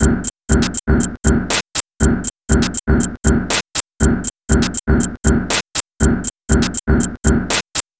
The assignment was to recreate a few drum breaks using non-drum base sounds of our choosing and then modifying them using DSP tricks.